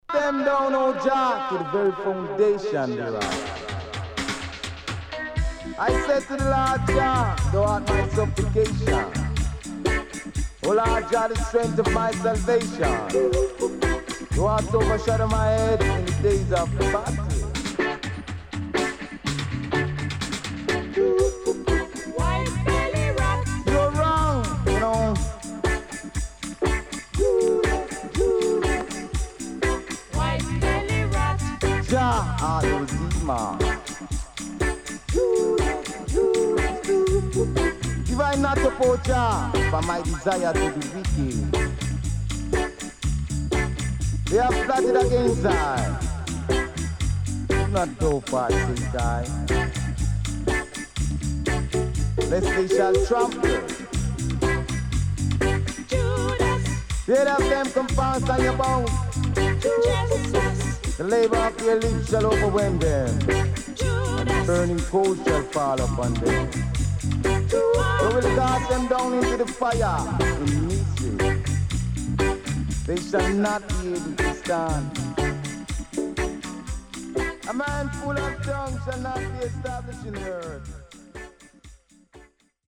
Nice Roots Vocal & Dubwise
SIDE A:少しチリノイズ入りますが良好です。